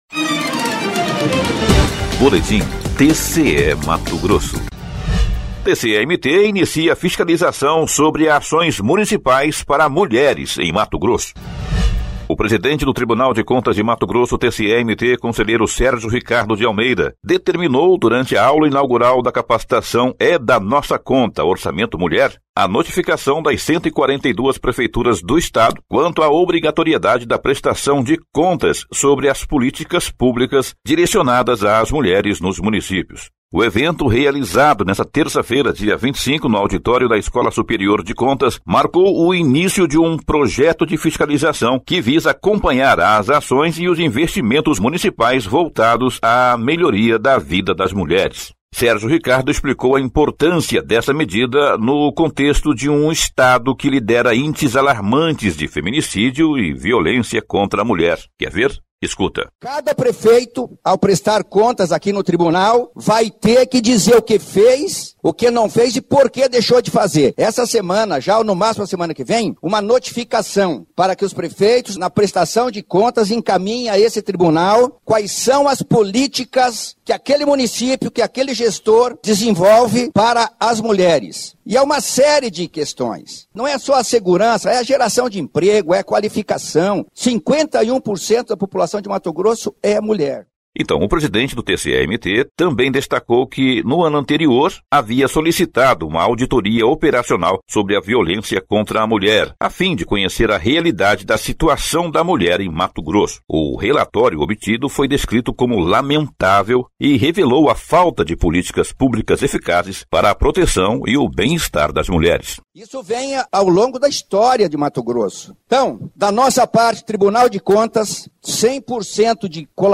Sonora: Sérgio Ricardo – conselheiro-presidente do TCE-MT
Sonora: Maria Luziane Ribeiro de Castro - defensora pública-geral em Mato Grosso